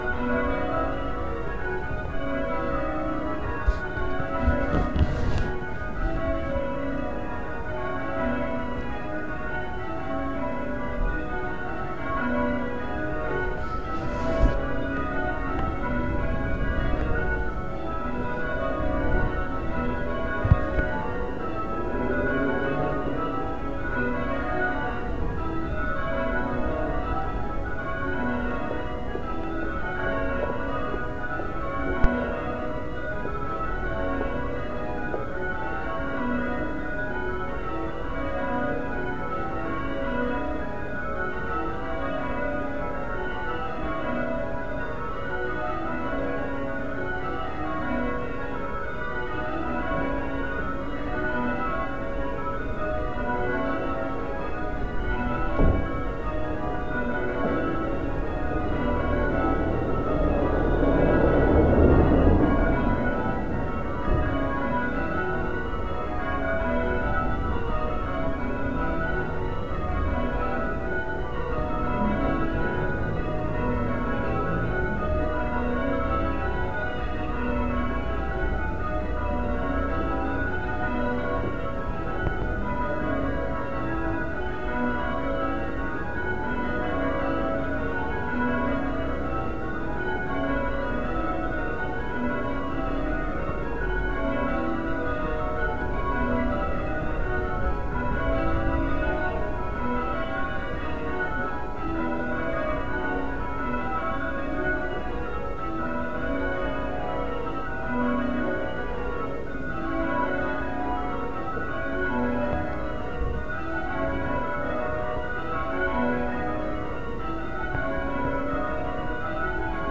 Mind ears as phone goes off loudly.